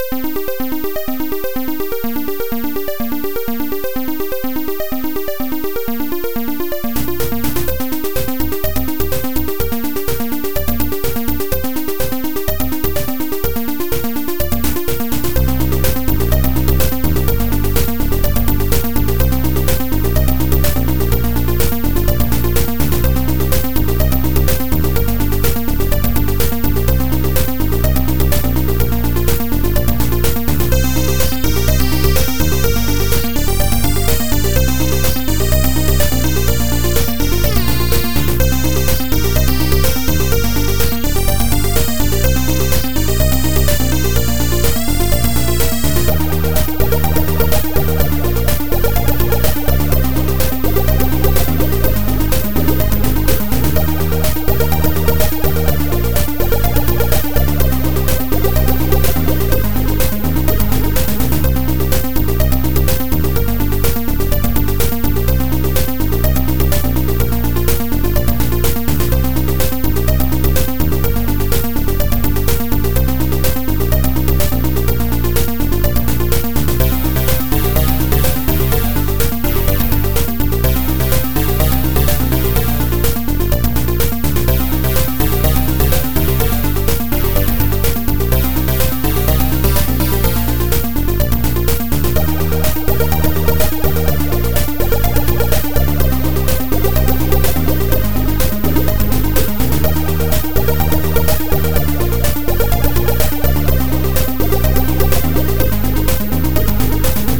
Surprise! Adlib Tracker 2 (version 9)